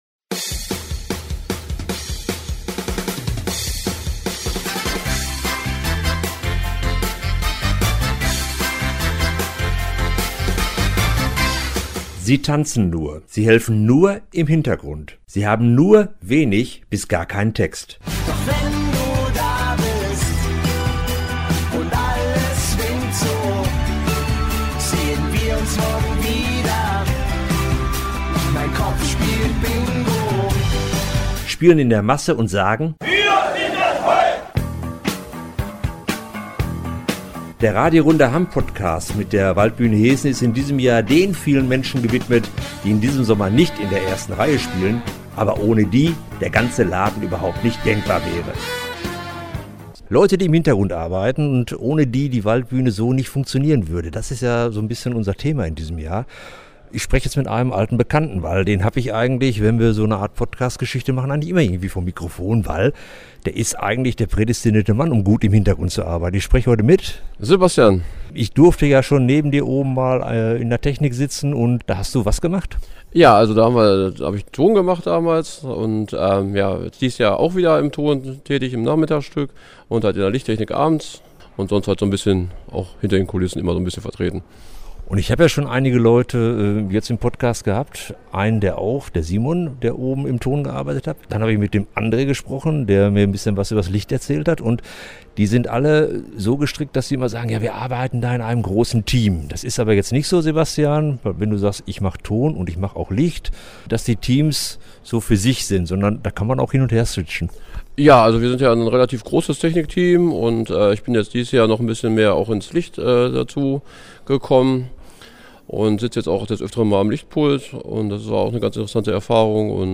Die treue Zuhörerschaft unserer jahrelangen Waldbühnen-Interviews kennt unseren heutigen Gesprächspartner auf jeden Fall